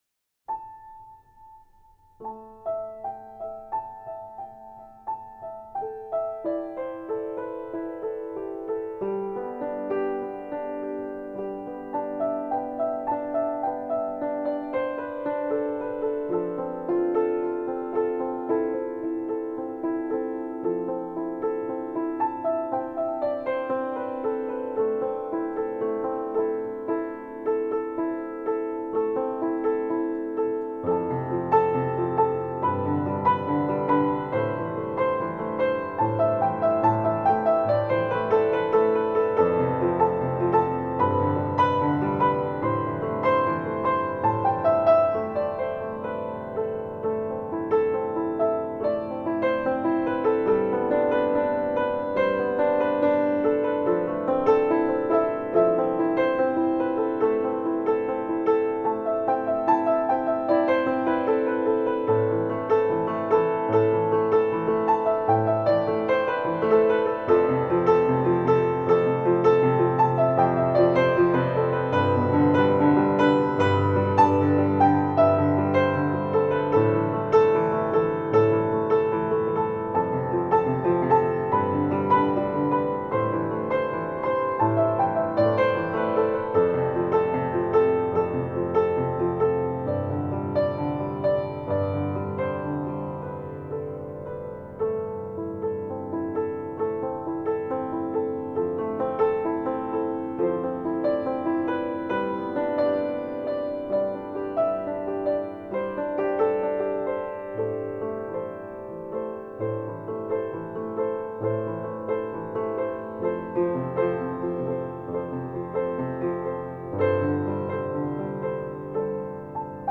音乐风格：Classical